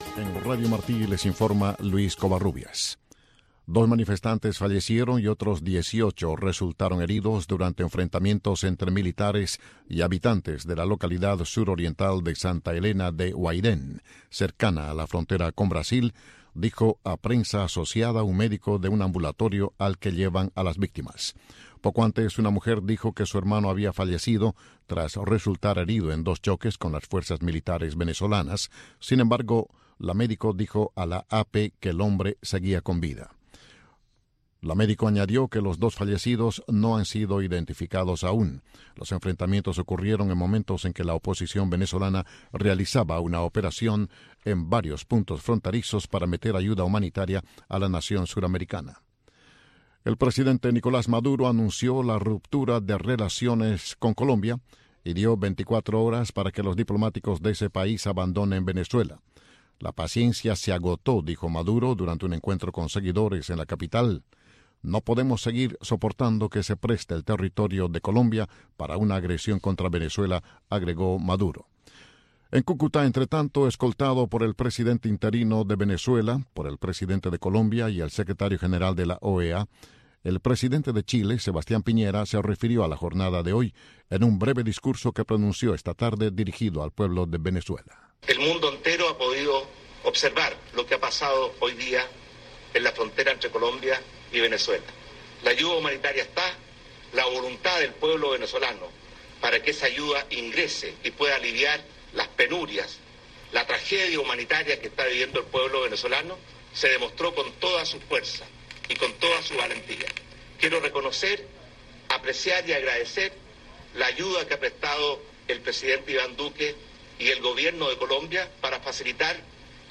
Radio Martí les ofrece un program especial en vivo sobre la situación en Venezuela.